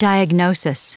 Prononciation du mot diagnosis en anglais (fichier audio)
Prononciation du mot : diagnosis